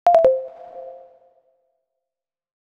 menuleave1.wav